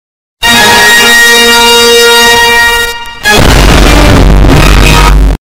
Blue Lobster Jumpscare Ear Rape